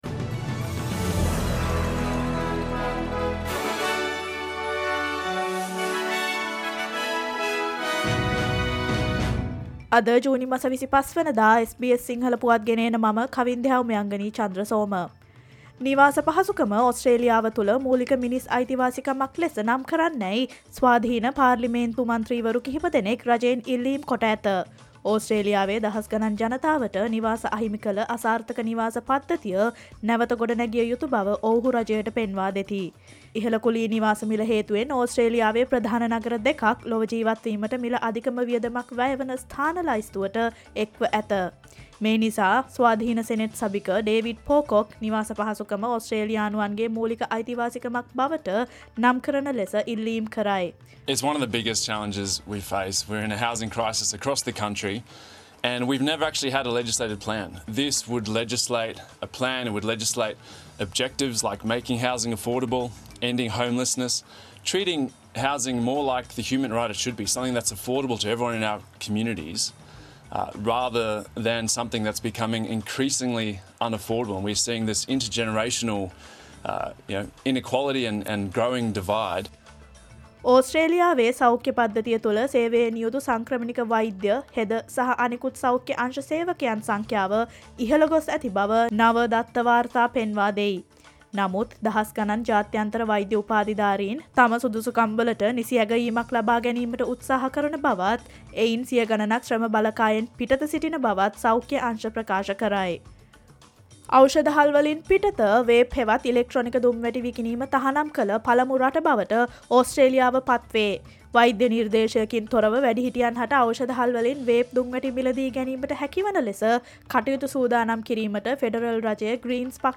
Australia's news in English, foreign and sports news in brief.